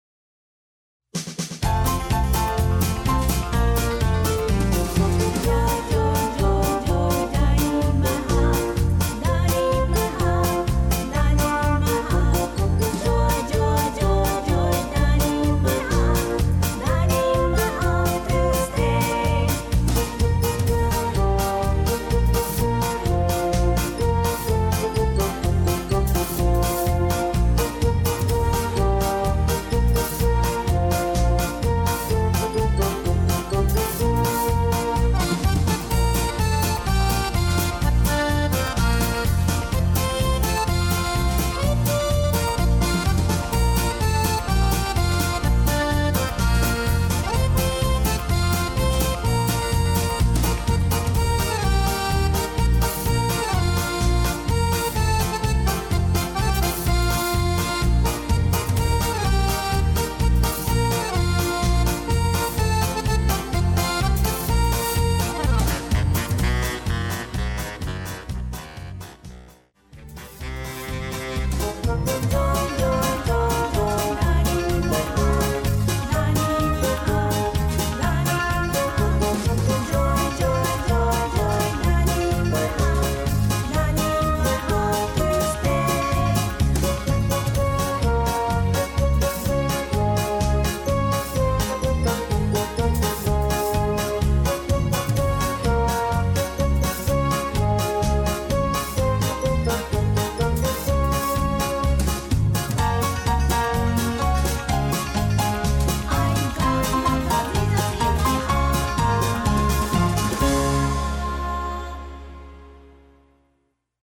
SC = Singing Call